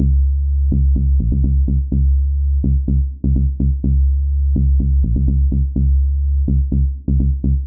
• Tech House Bass Rhythm Sustained and Punchy - C sharp.wav
Loudest frequency 90 Hz
Tech_House_Bass_Rhythm_Sustained_and_Punchy_-_C_sharp_qez.wav